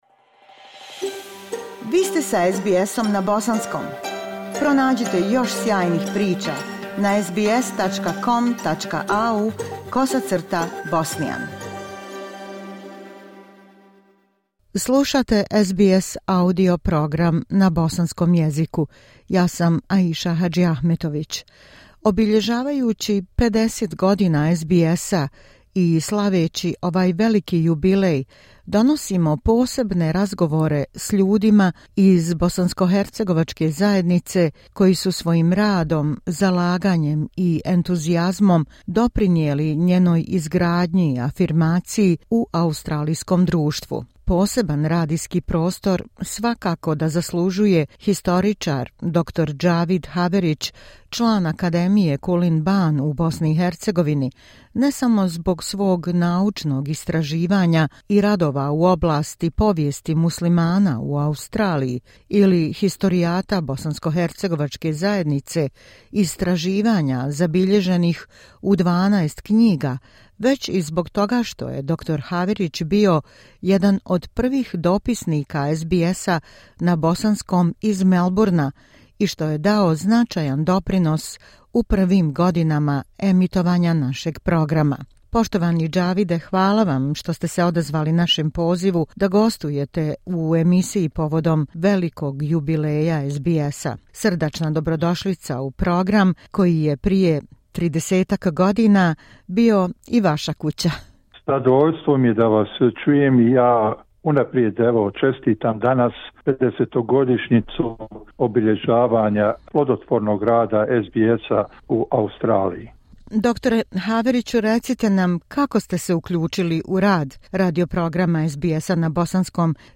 Obilježavajući 50 godina SBS-a i slaveći ovaj veliki jubilej donosimo posebne razgovore s ljudima iz bh zajednice koji su svojim radom i zalaganjem doprinijeli njenoj izgradnji i afirmaciji u australijskom društvu.